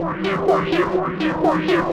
RI_ArpegiFex_125-04.wav